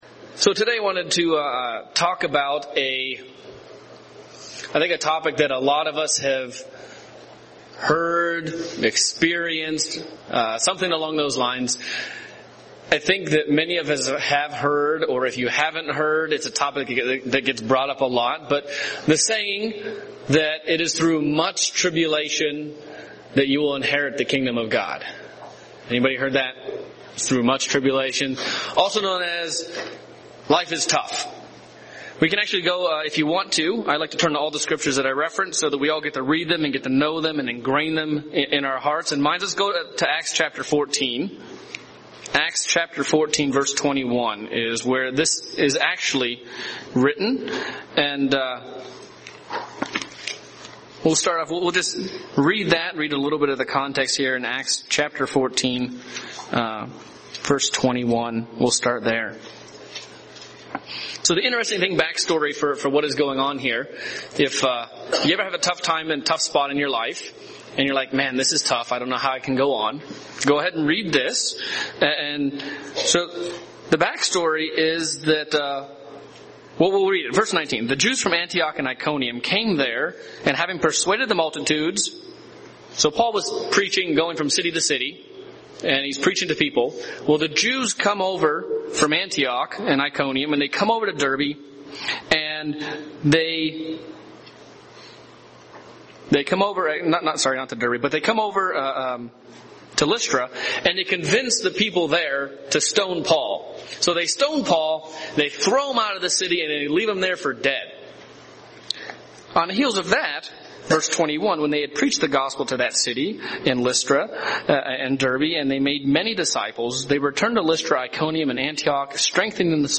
In this sermon we explore what trials are, what they aren't, and how we can approach them to better prepare for the coming Kingdom of God.
Given in Albuquerque, NM